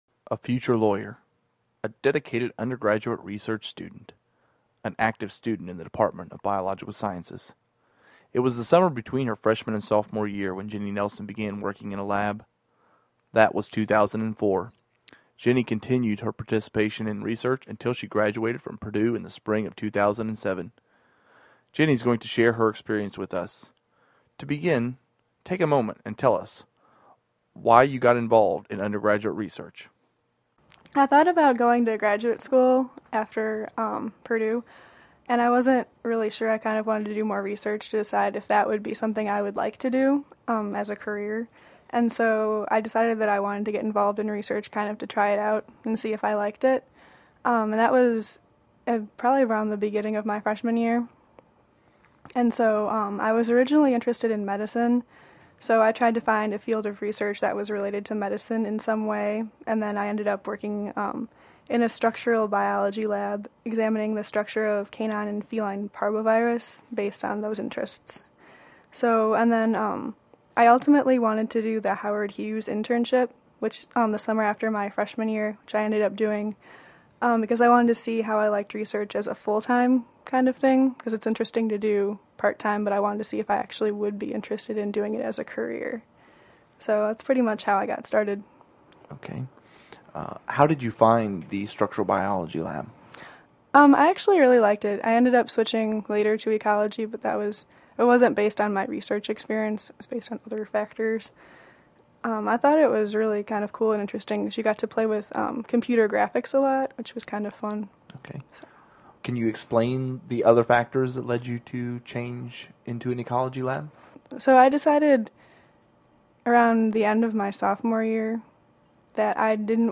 Don't believe us? Listen to these interviews with our undergraduate research students!
Interview (mp3 file) 8 min 20 sec.